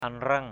/a-nrʌŋ/ (d.) rạ = chaume. rice straw. cuh anreng blaoh ka mâng la-aua c~H an$ _b<*H k% m/ la&% đốt rạ xong rồi mới cày.